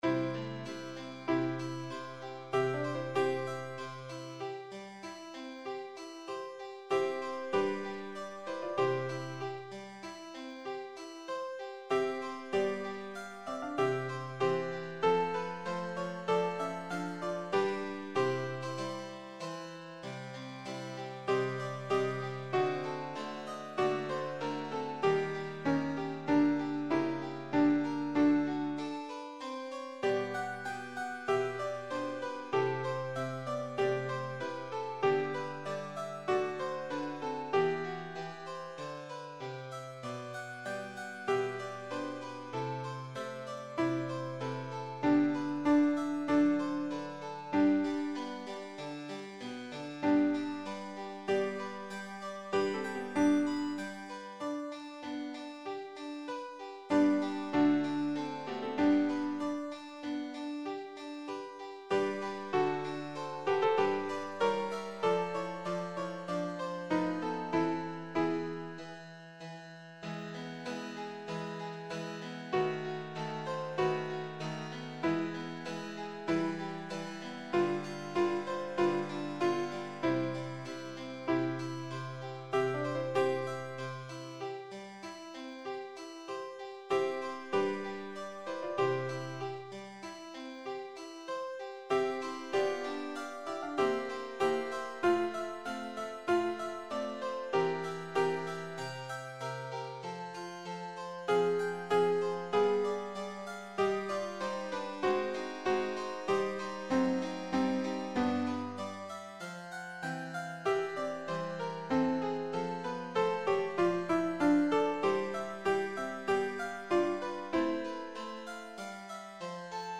Sans paroles
Voix + chœur en sourdine